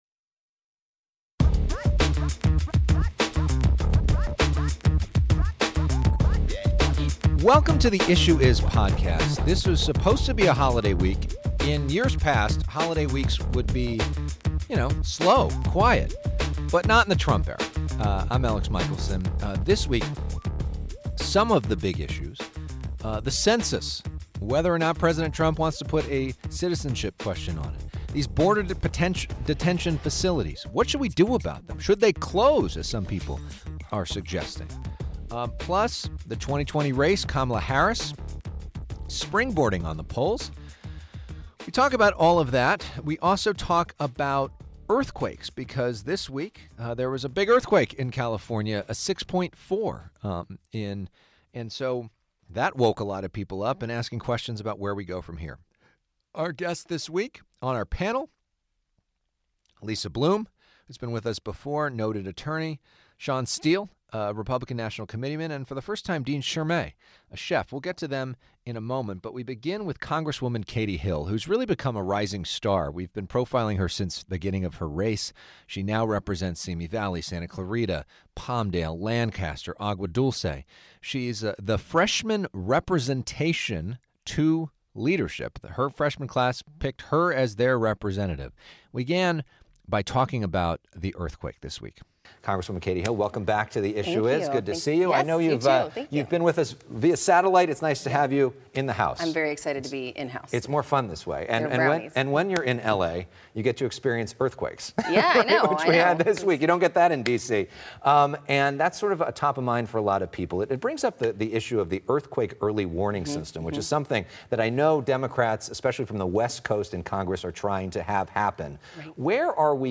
broadcast from FOX 11 Studios in Los Angeles.